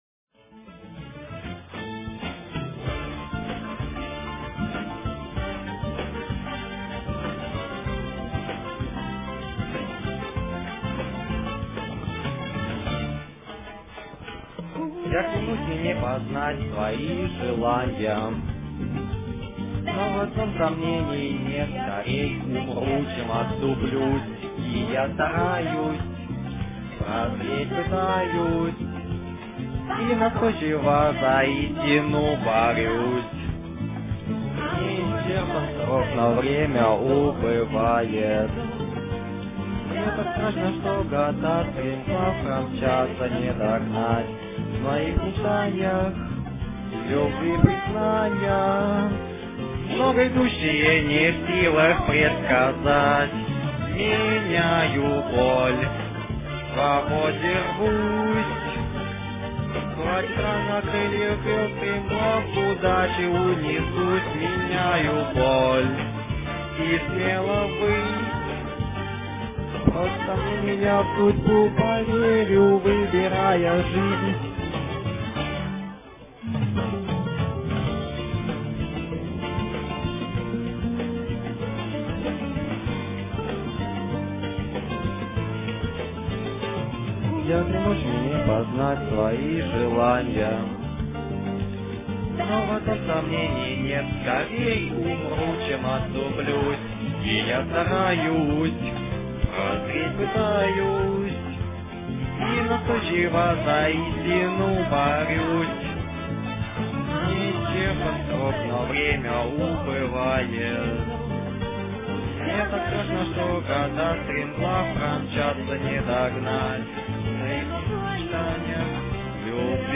Песня соунд - трек
Мой перевод... моя озвучка...Пишите комментарии...